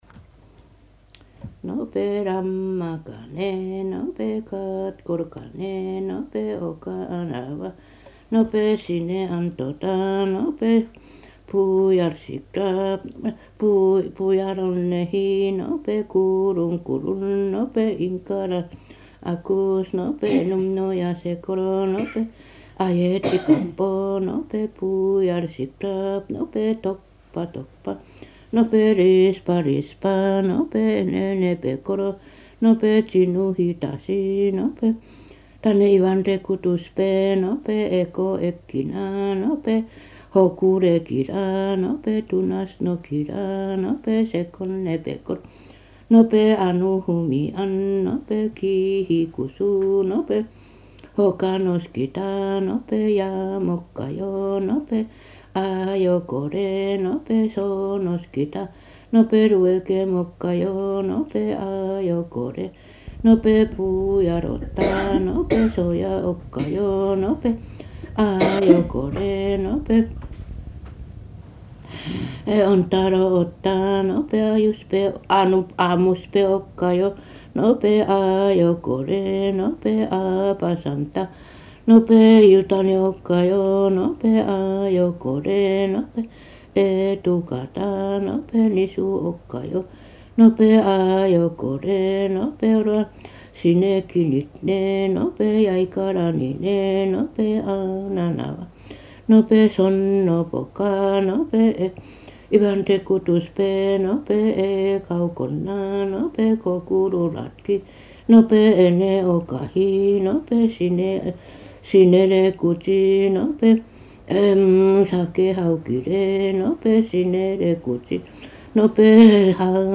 [17-10 神謡 mythic epics]【アイヌ語】5:02